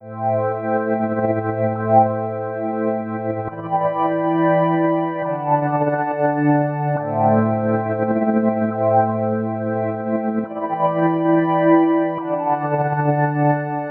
You can hear that some kind of weird tremolo effect is affecting our pad.
eq effect plus thor.mp3